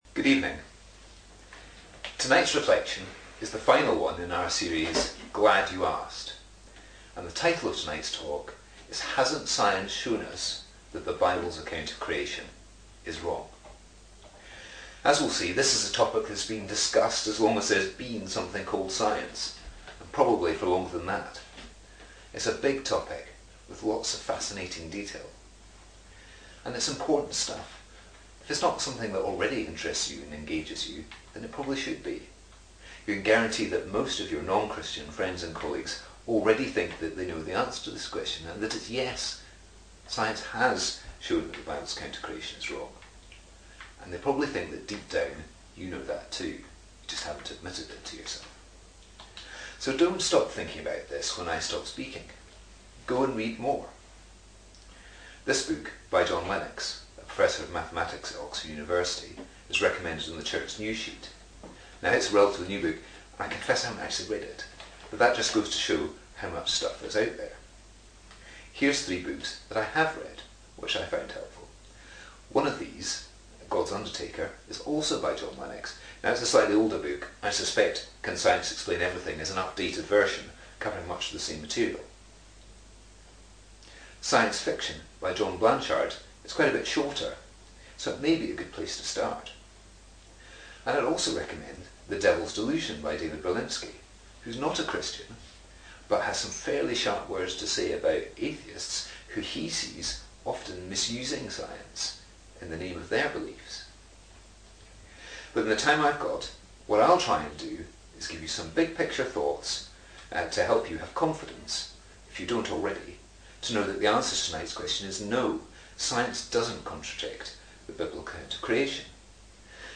Evening Service, Sunday, September 27, 2020 Most people assume that, since Galileo and Darwin, science and Christianity have been in conflict about the nature of creation, and that we can now be sure this is a debate science has won.